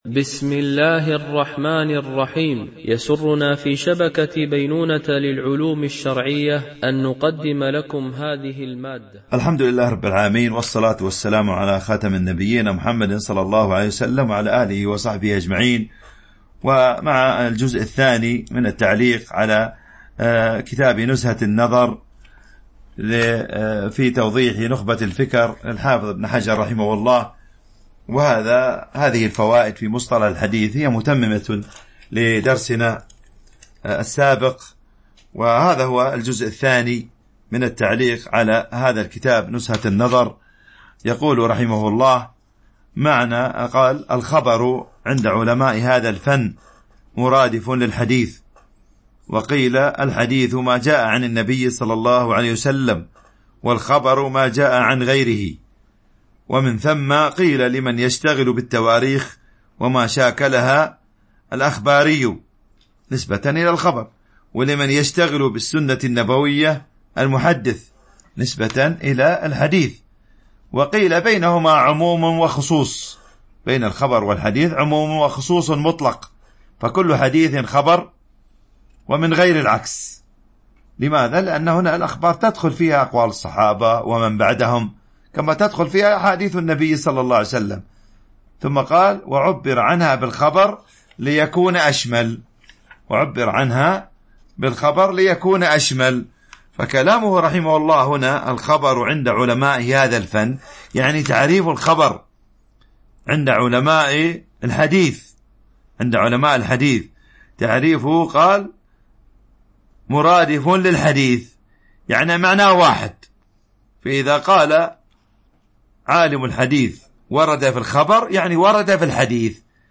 شرح نزهة النظر في توضيح نخبة الفكر - الدرس 2